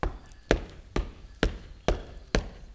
FOOTSTOMP1-L.wav